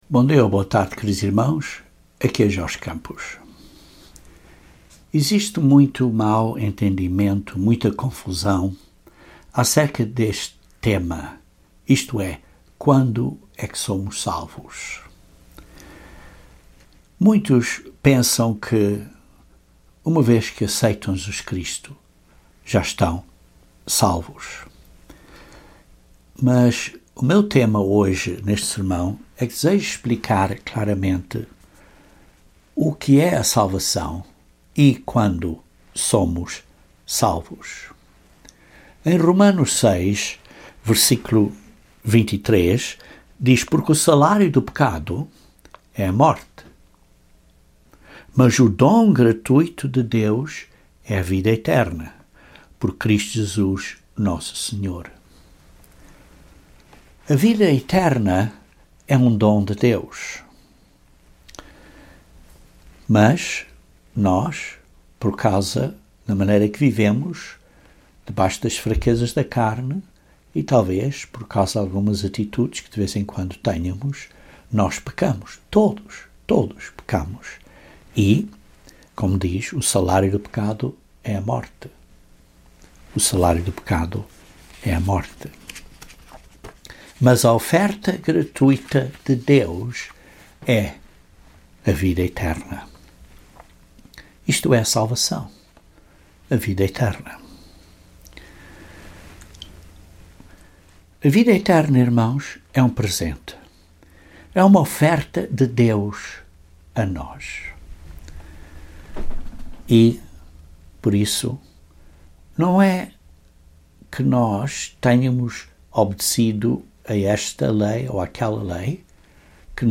Esta é uma pergunta importante, porque temos que continuar vencendo as fraquezas da carne até ao fim. Este sermão aborda este tema, de que a salvação é um processo.